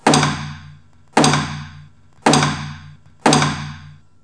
Trống Chiến